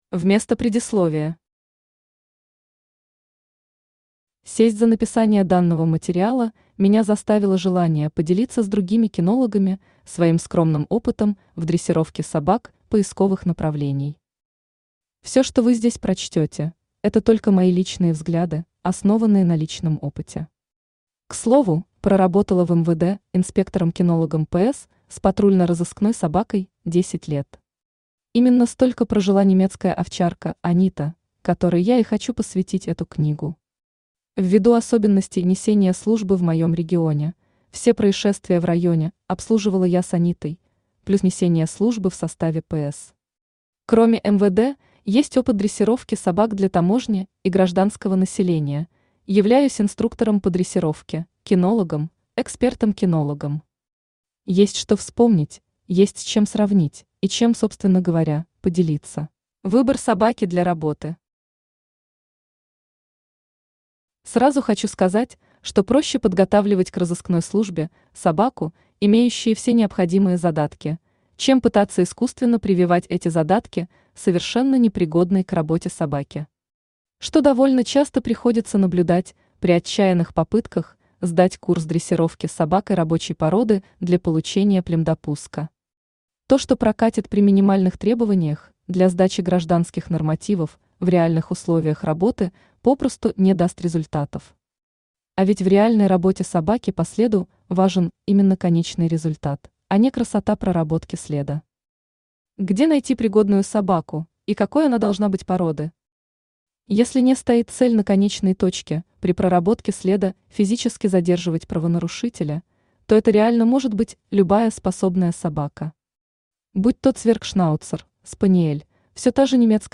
Аудиокнига От апортировки к проработке следа | Библиотека аудиокниг
Aудиокнига От апортировки к проработке следа Автор Татьяна Владимировна Худякова Читает аудиокнигу Авточтец ЛитРес.